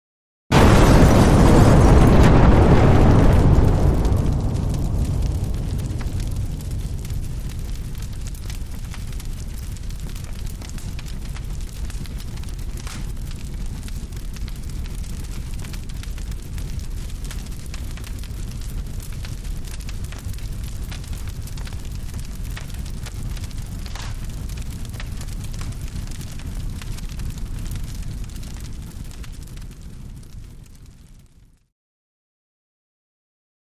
House Explosion